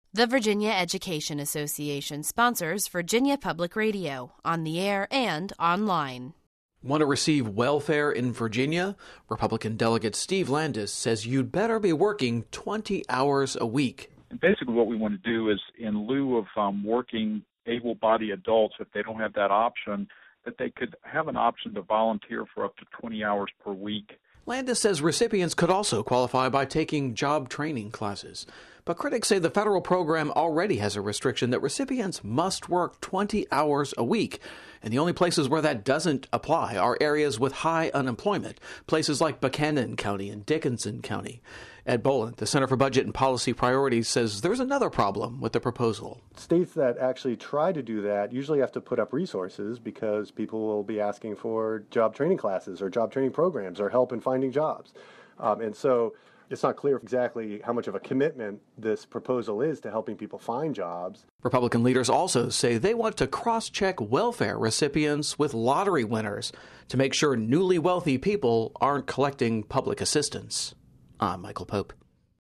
vpr-welfare-reform.mp3